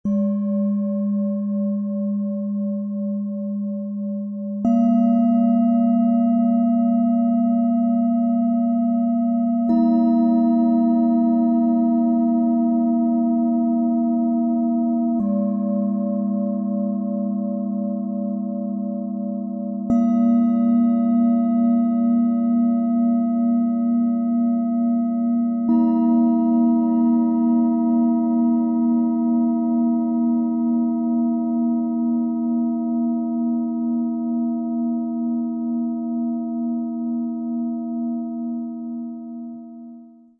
Vom Fühlen zum Verstehen: meditativ, klärend, ruhig - Set aus 3 Klangschalen, Ø 12,8 - 14,7 cm, 1,04 kg
Tiefster Ton – entspannend, öffnend, zentrierend
Mittlerer Ton – heiter, fragend, gefühlvoll
Höchster Ton – klar, lichtvoll, lösungsorientiert
Der mitgelieferte Klöppel ist ideal auf die kleinen Schalen abgestimmt und bringt deren klare, sanfte Töne wunderbar zur Geltung.
MaterialBronze